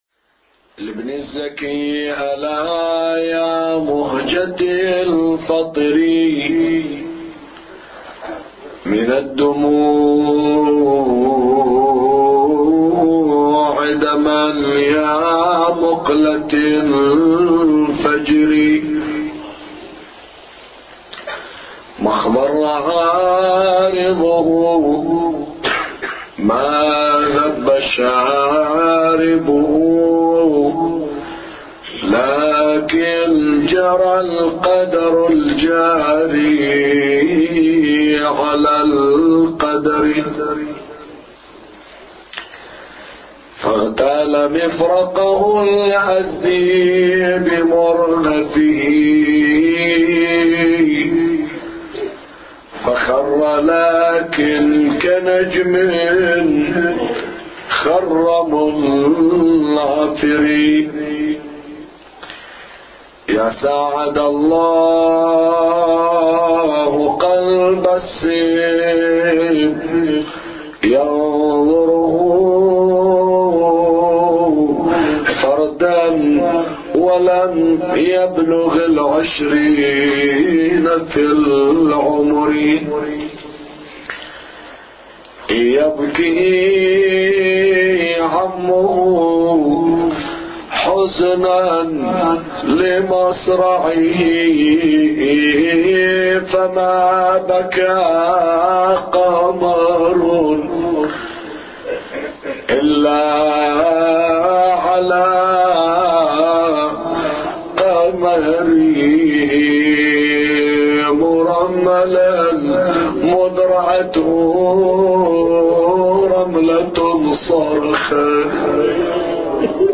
أبيات حسينية – ليلة الثامن من شهر محرم